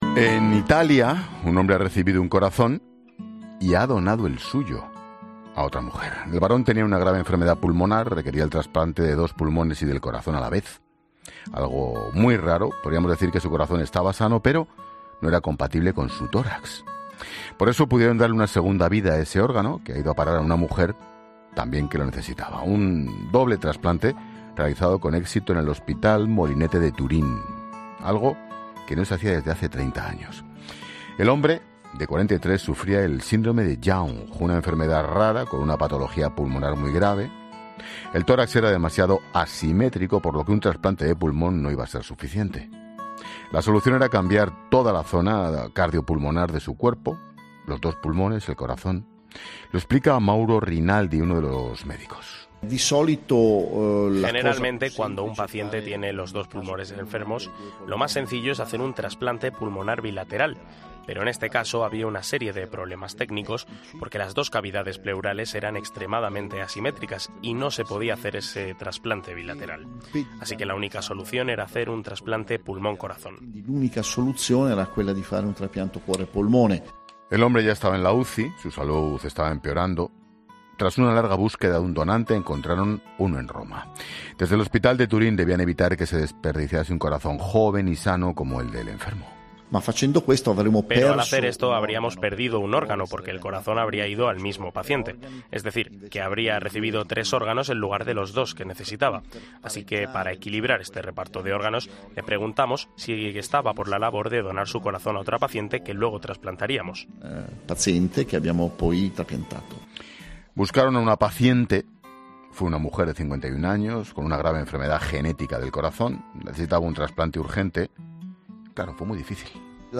uno de los médicos de que ha participado en la operación, explica en La Linterna de COPE por qué decidieron trasplantarle un corazón y donar el suyo que estaba sano